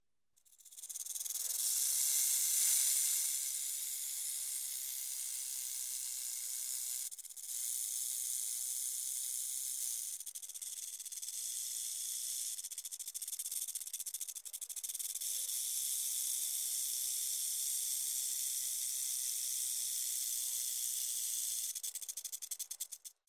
the-sound-of-hissing-snakes